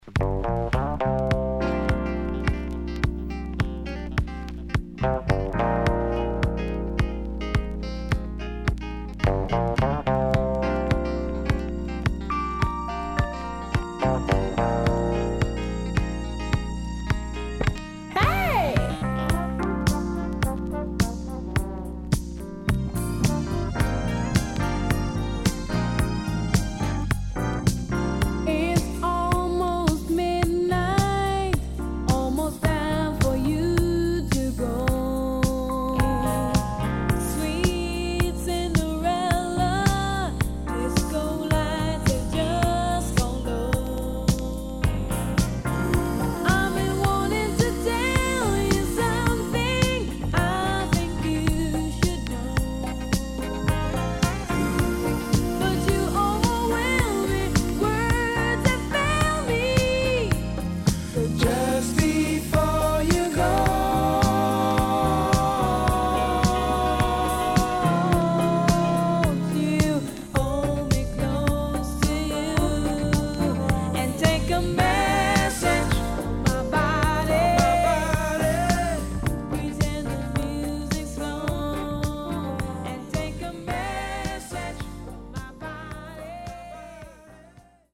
80年全曲Version接続のLong Mix!Superb Lovers Album
SIDE A:少しノイズ入りますが良好です。